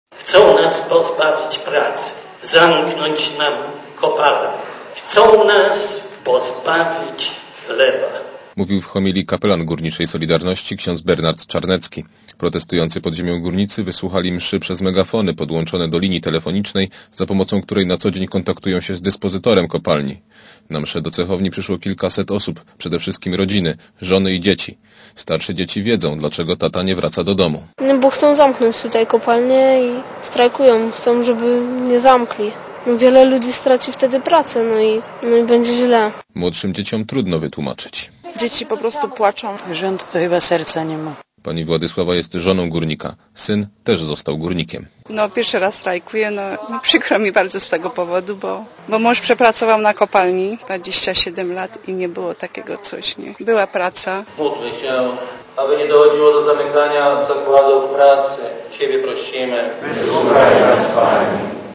Posłuchaj relacji reportera Radia Zet (240 KB) audio